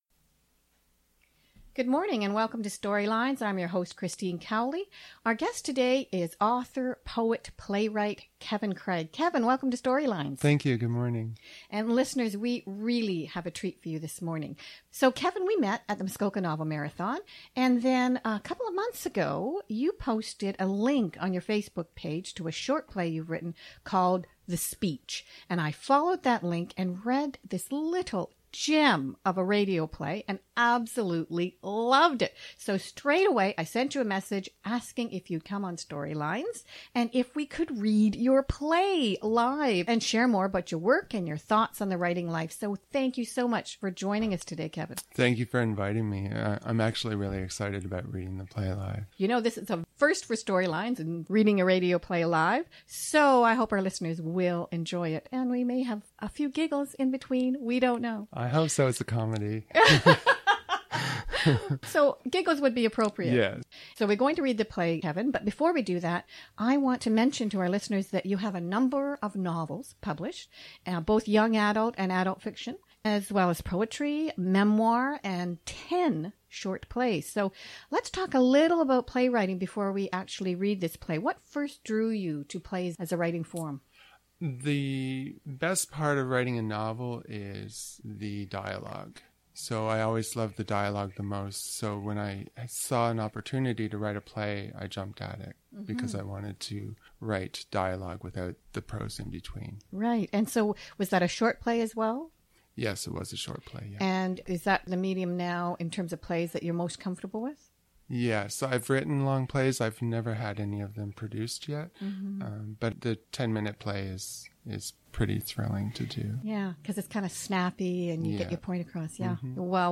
The Recordings of My STORYLINES Interview & Play Performance from Hunter’s Bay Radio…